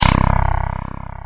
bass02
bass02_filterMe01.ra